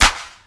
SouthSide Clap (19).wav